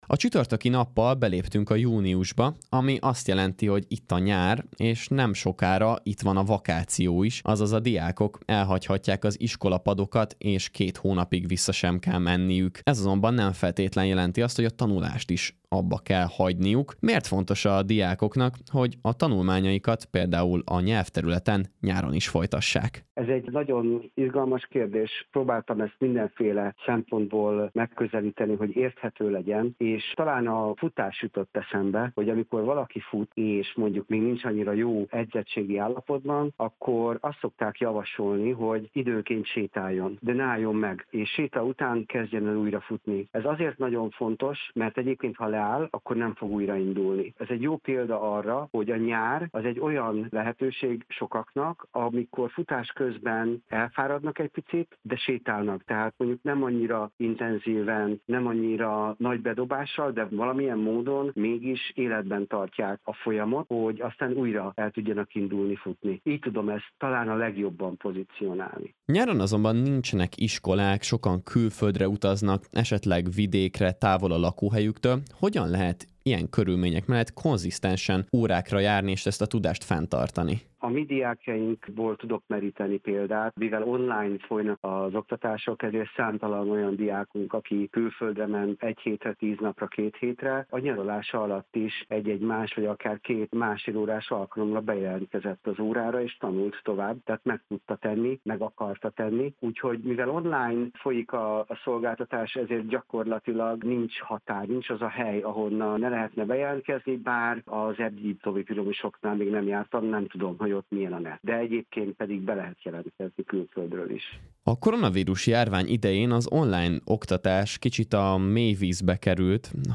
Rádióinterjú